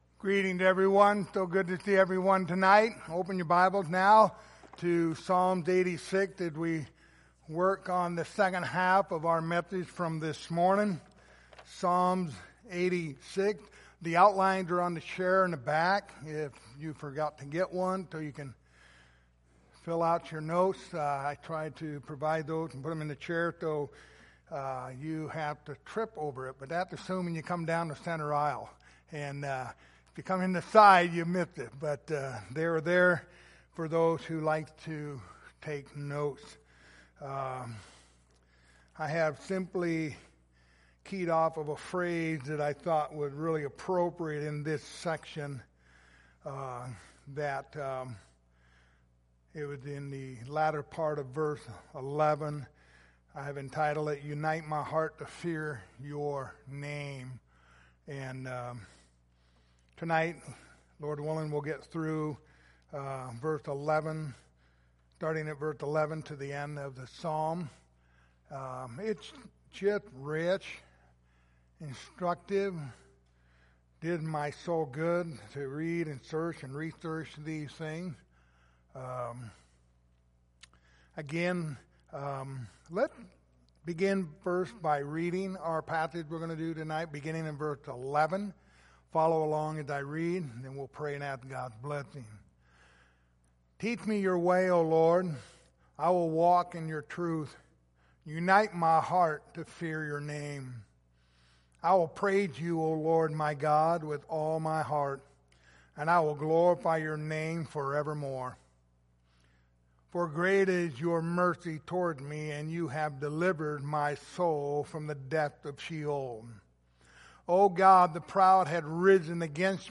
Passage: Psalms 86:11-17 Service Type: Sunday Evening